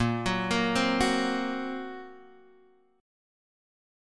A#sus2#5 chord